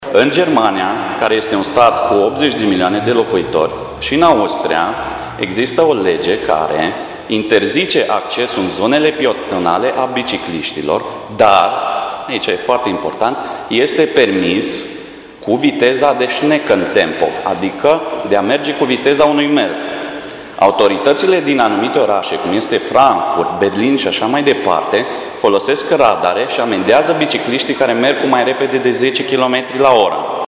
Cetățenii prezenți la dezbatere au venit cu argumente solide și propuneri concrete.
Dezbatere-PMT-cetatean-1.mp3